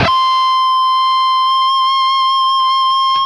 LEAD C 5 CUT.wav